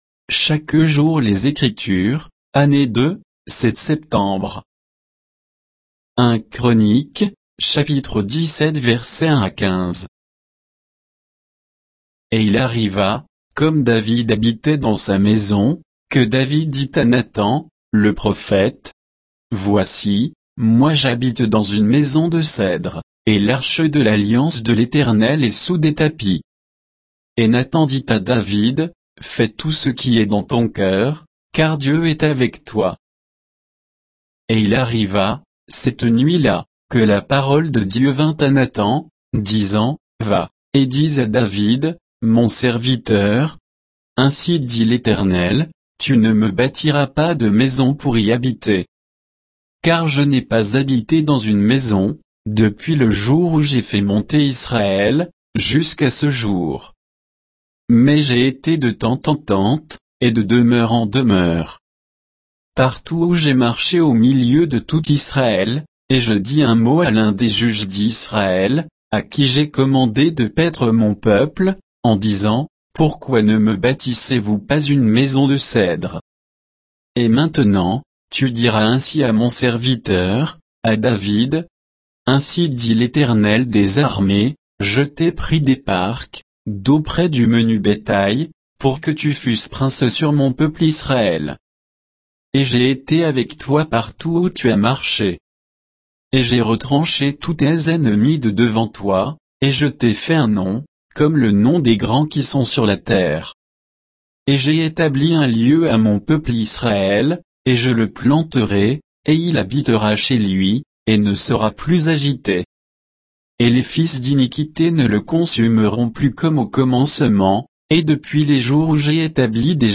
Méditation quoditienne de Chaque jour les Écritures sur 1 Chroniques 17